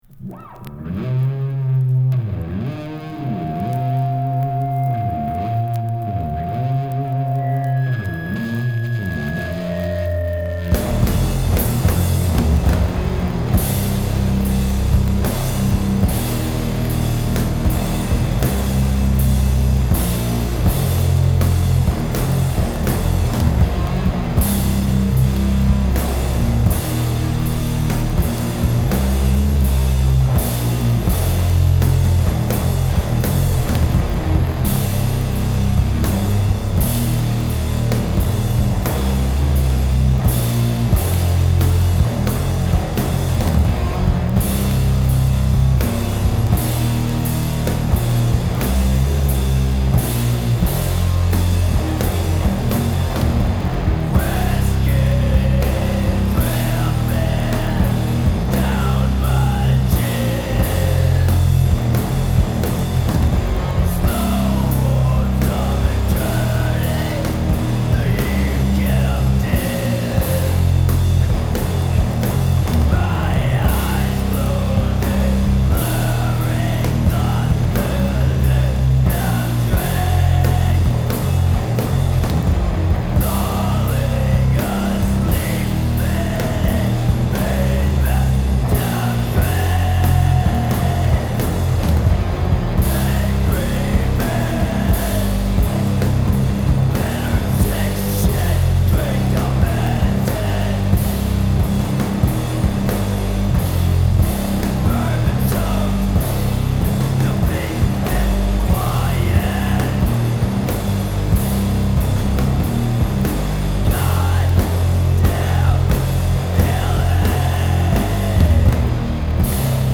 Bass
Guitar/Vocals
Drums
Metal